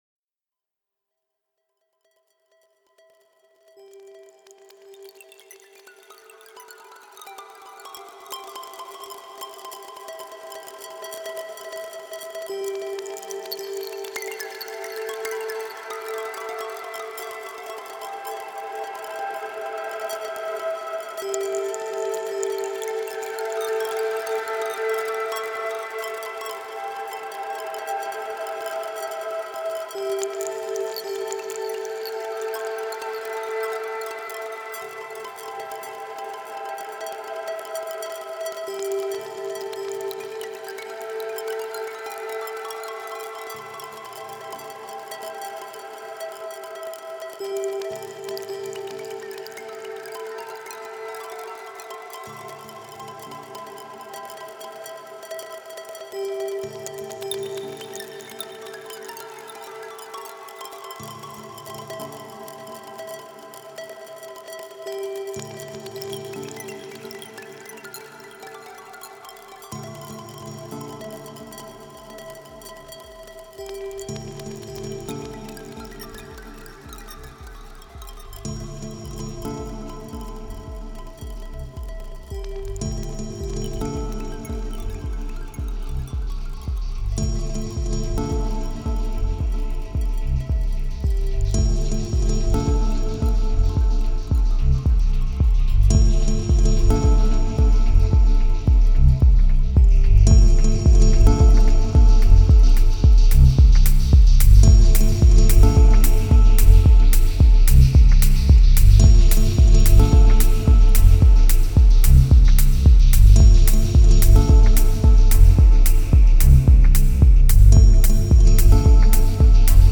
Genre: Dub Techno/Ambient/Deep House.